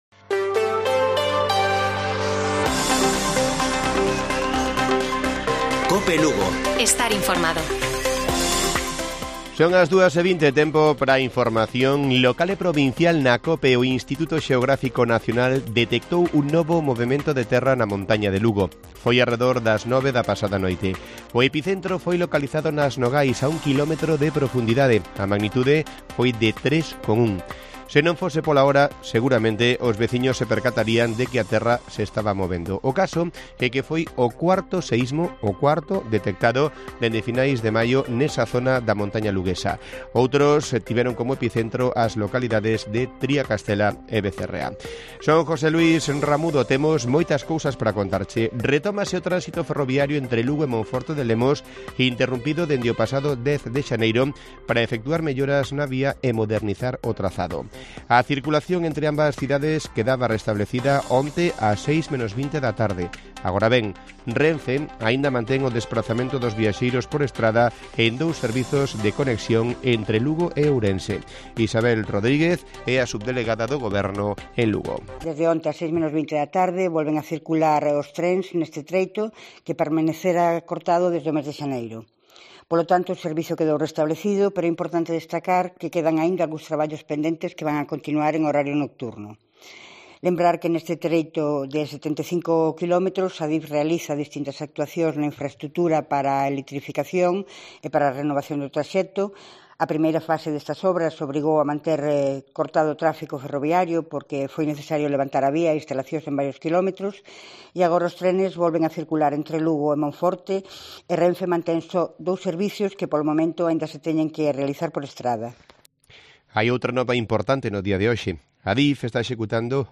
Informativo Mediodía de Cope Lugo. 30 DE JUNIO. 14:20 horas